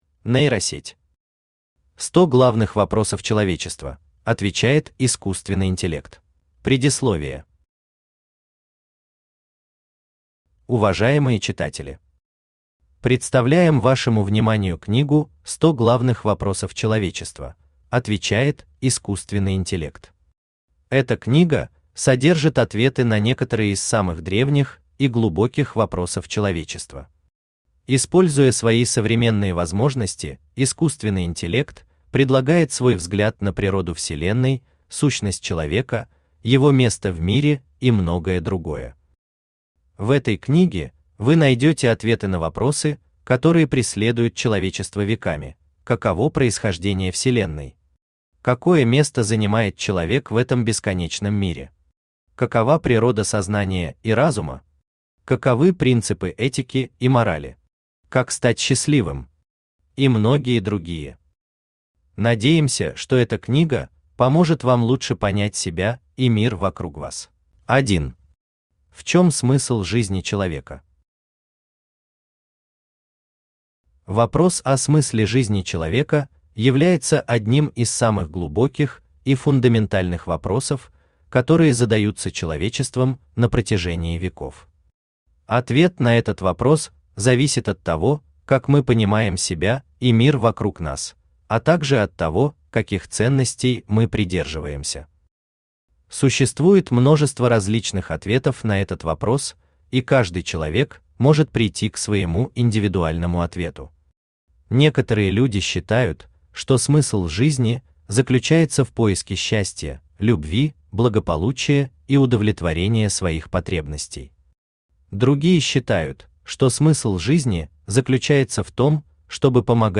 Аудиокнига 100 главных вопросов человечества: отвечает искусственный интеллект | Библиотека аудиокниг
Aудиокнига 100 главных вопросов человечества: отвечает искусственный интеллект Автор Нейросеть Читает аудиокнигу Авточтец ЛитРес.